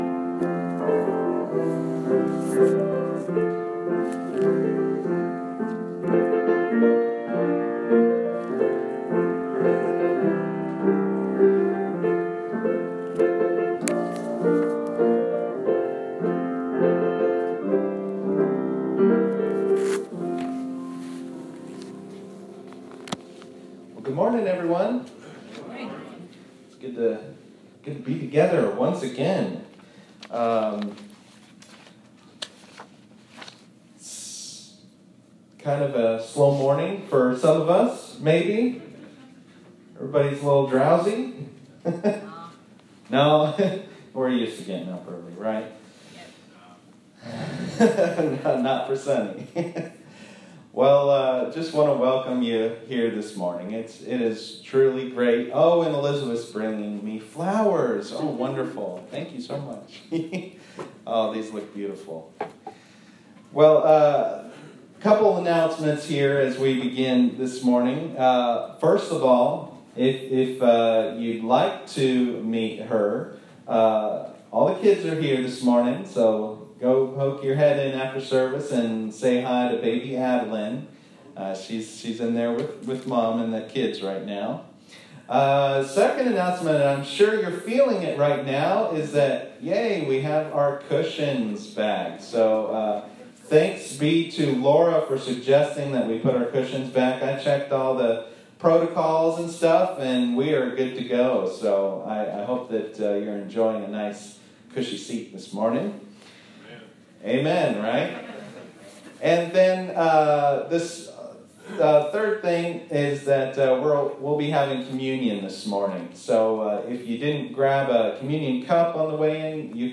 This is Part 4 of a 7-Part sermon series titled “Easter according to Paul” preached at Mt. Gilead UMC in Georgetown, KY.
If you want to listen to the whole worship service, click this first link below.